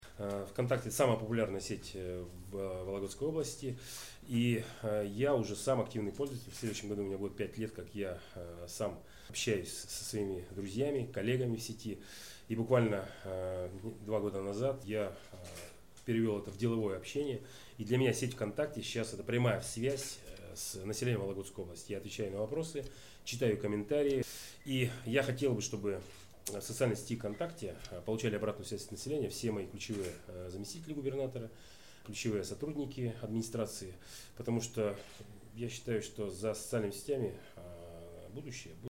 Олег Кувшинников рассказал, что и сам является пользователем «ВКонтакте»